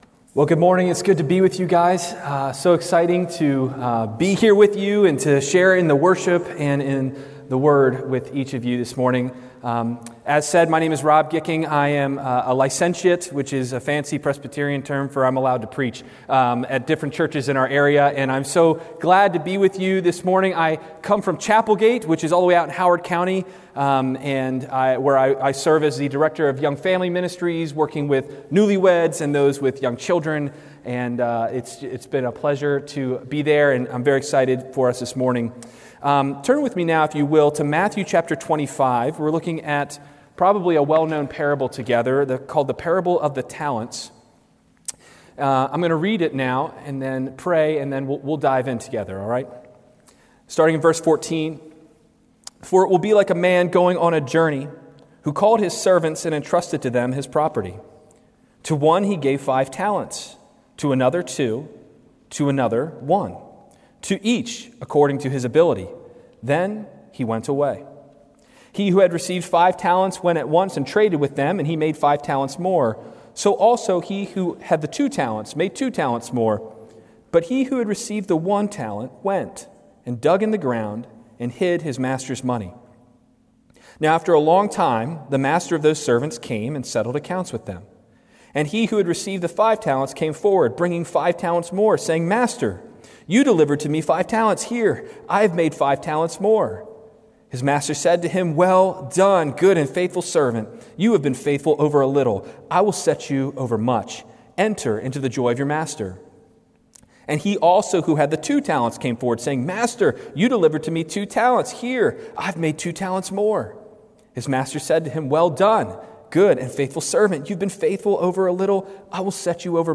From Series: "Guest Sermons"